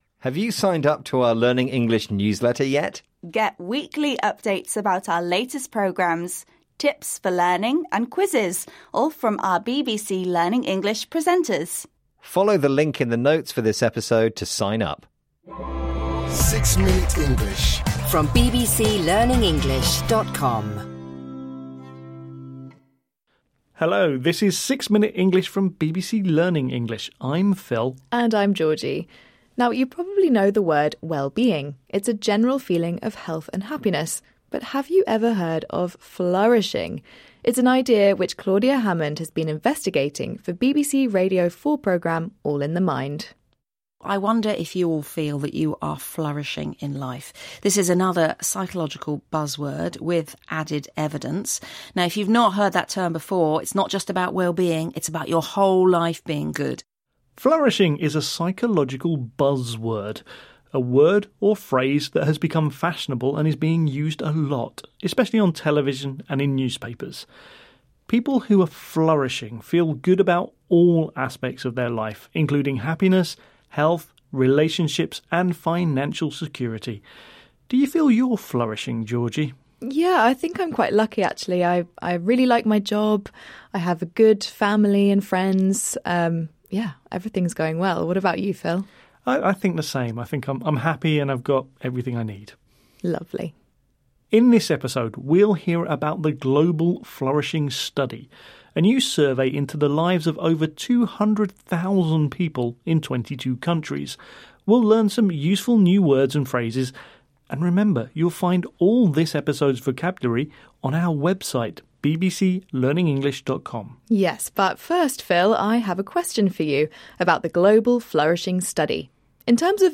در هر قسمت ۶ دقیقه‌ای، دو میزبان درباره موضوعات روزمره و جالب گفتگو کرده و واژگان کلیدی را به شما آموزش می‌دهند.
• لهجه: British.